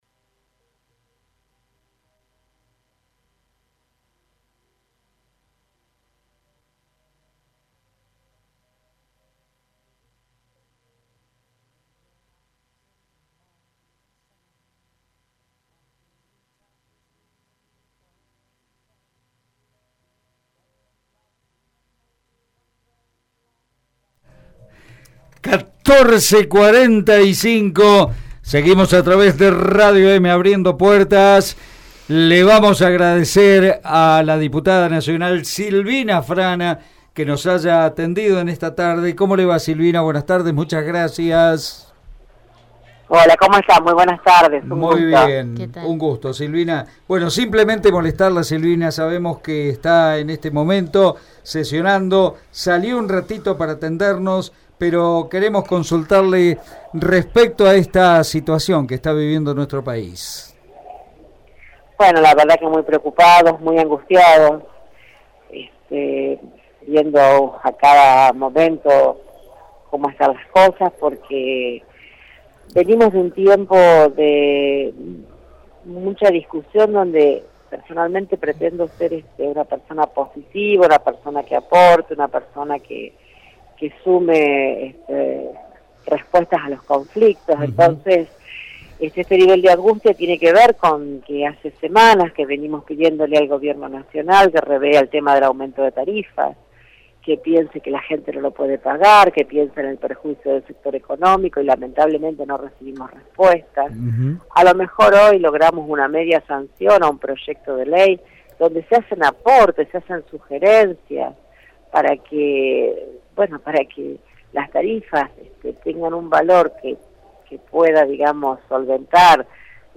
La Diputada Nacional por Santa Fe, Silvina Frana, habló en Radio EME sobre el tratamiento que se le está dando a los tarifazos en la Cámara de Diputados.